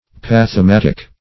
Search Result for " pathematic" : The Collaborative International Dictionary of English v.0.48: pathematic \path`e*mat"ic\ (p[a^]th`[-e]*m[a^]t"[i^]k), a. [Gr. paqhmatiko`s, fr. pa`qhma a suffering, paqei^n, to suffer.]